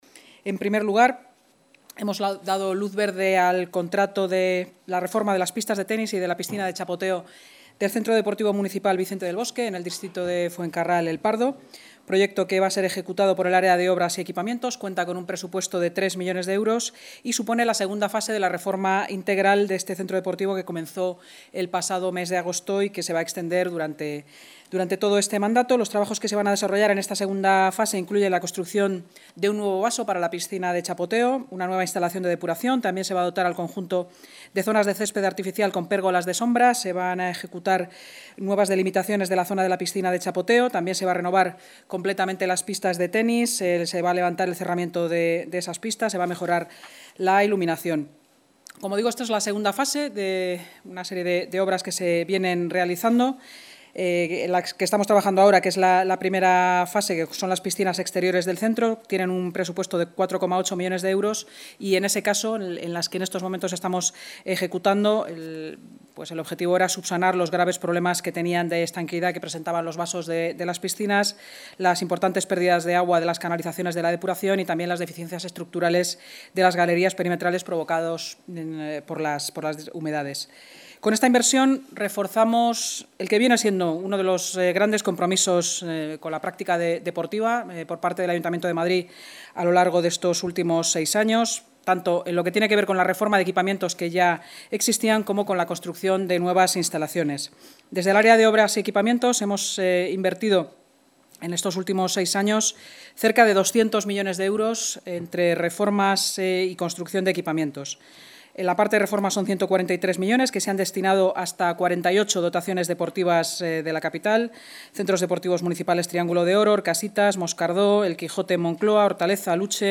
Nueva ventana:Intervención de la vicealcaldesa y portavoz municipal, Inma Sanz, en la rueda de prensa posterior a la Junta de Gobierno